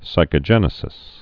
(sīkə-jĕnĭ-sĭs)